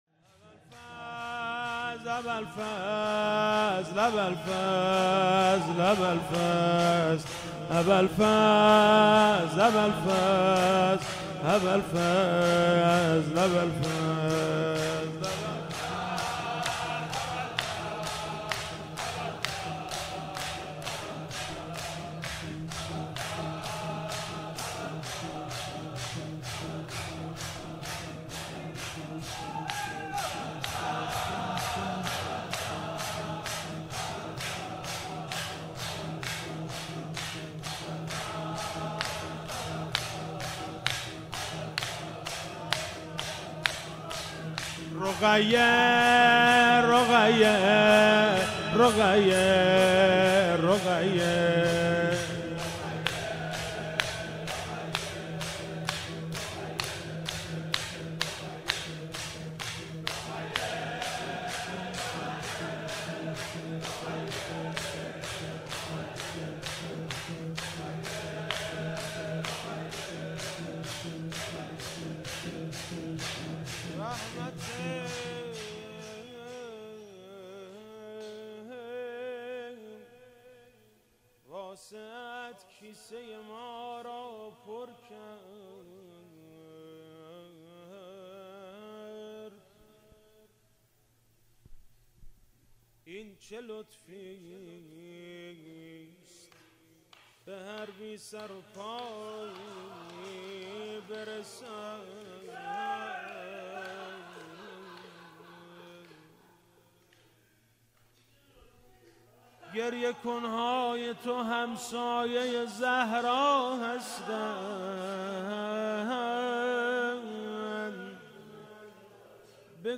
فاطمیه 96 - شب چهارم - شور - ابالفضل ابالفضل ابالفضل